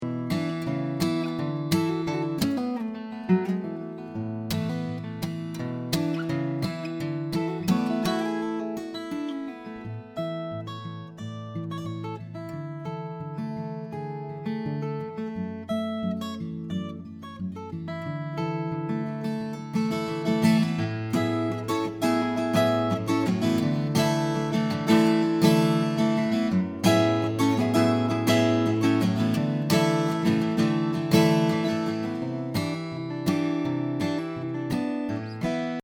Akustikgitarre im Wohnzimmer stereo oder mono aufnehmen?
Ich möchte meine Stücke (Akustikgitarre solo) in meinem Wohnzimmer (sehr trocken - Bücherregale) aufnehmen.
Zur Info: DIe Gitarre ist jetzt eine Martin (in den anderen Beispielen wars eine Maton).